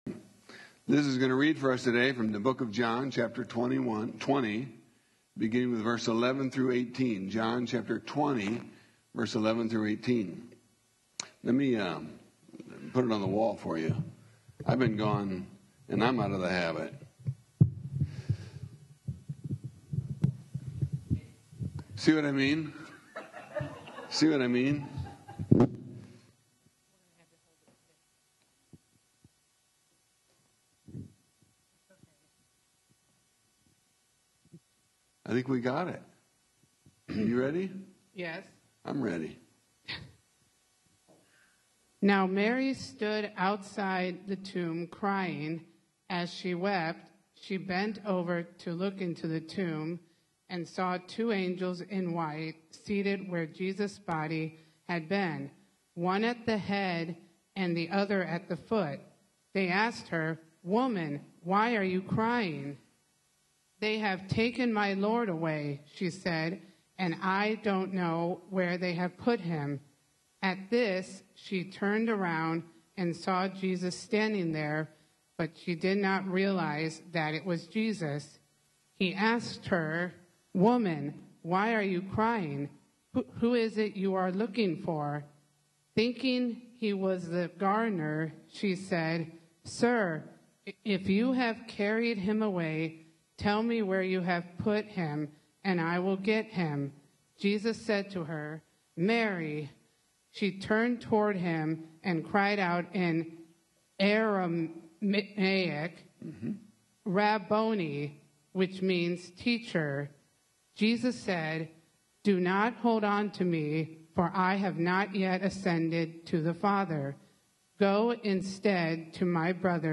Hear recorded versions of our Sunday sermons at your leisure, in the comfort of your own personal space.